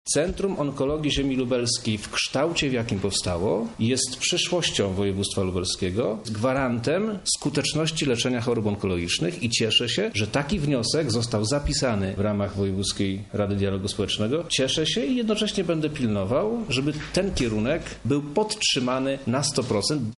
Zapewniał wojewoda lubelski Przemysław Czarnek.